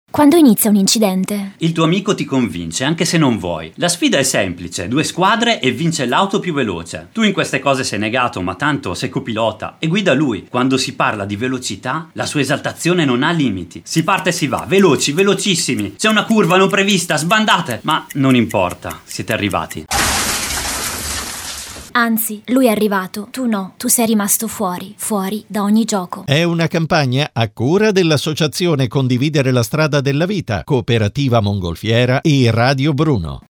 Continuate a seguirci sui nostri social, per scoprire tutti gli spot radio della campagna “Quando inizia un incidente?”